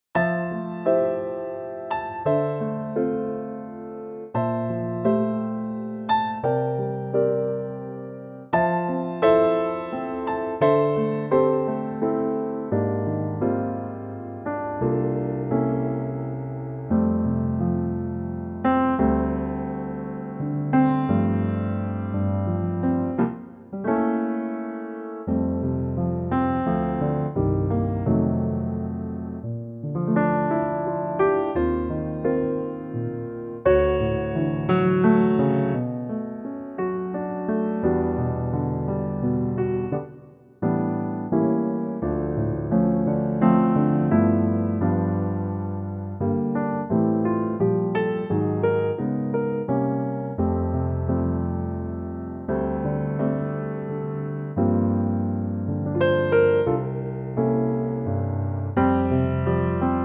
key - F - vocal range - D to A (huge range)